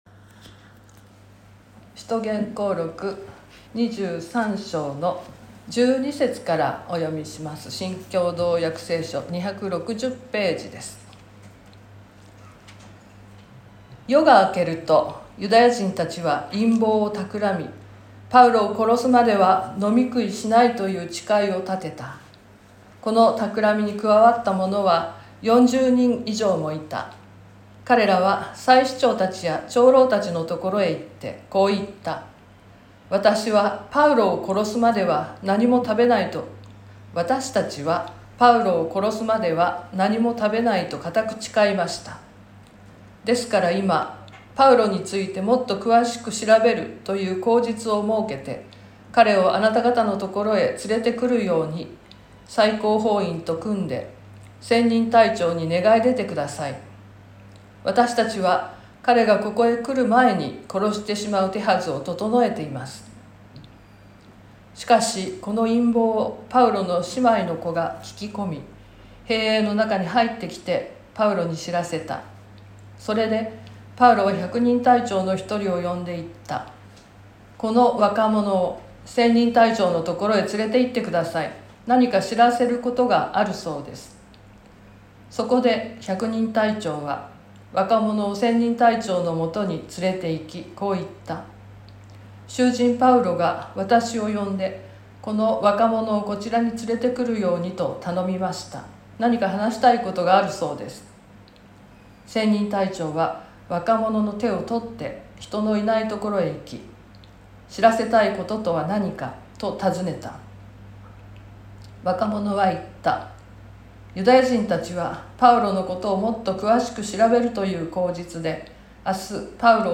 2023年11月05日朝の礼拝「パウロ暗殺の陰謀」川越教会
説教アーカイブ。
音声ファイル 礼拝説教を録音した音声ファイルを公開しています。